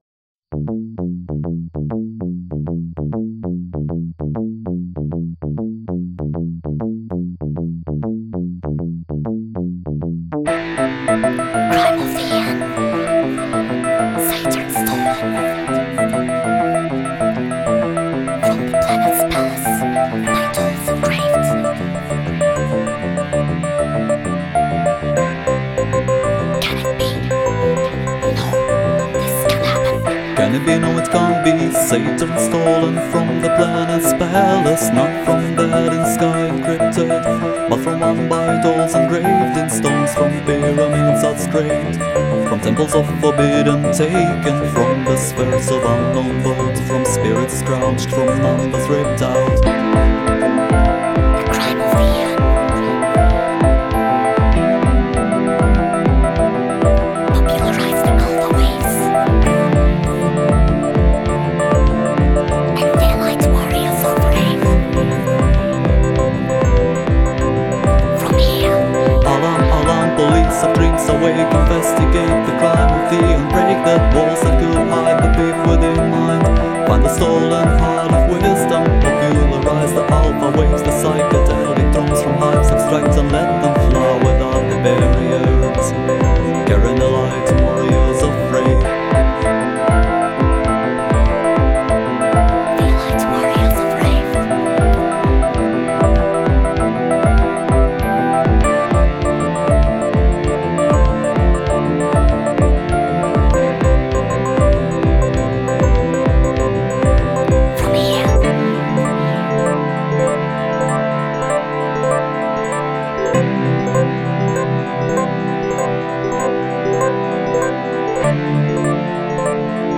первое студийное демо.